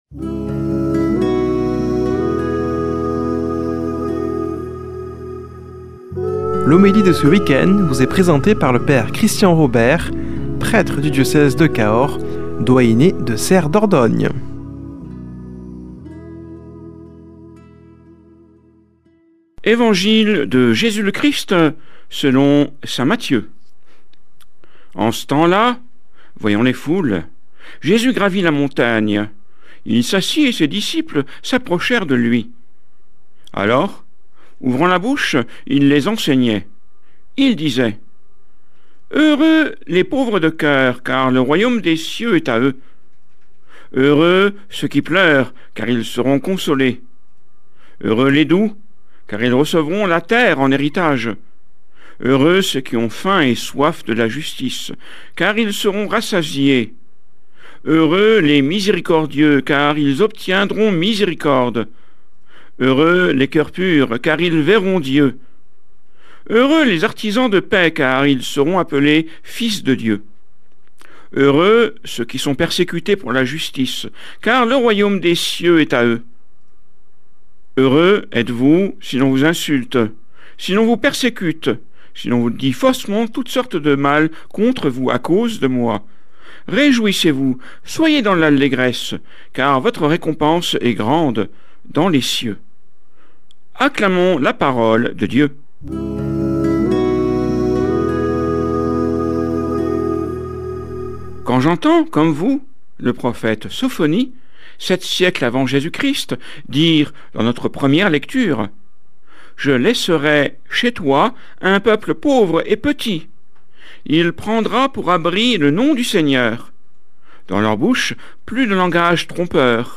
Homélie du 31 janv.